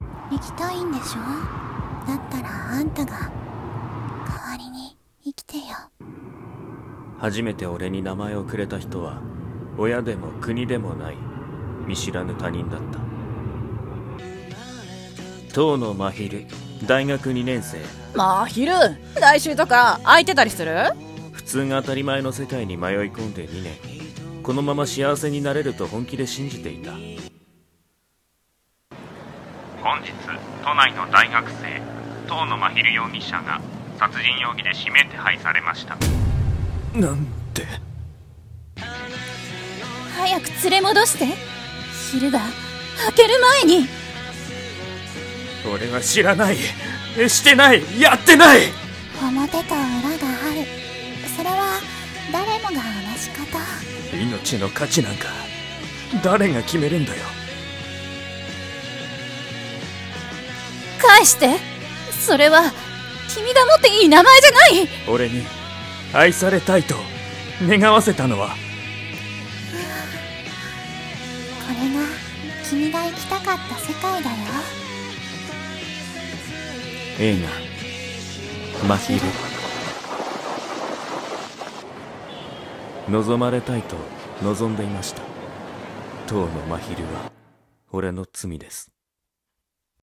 【映画予告風声劇】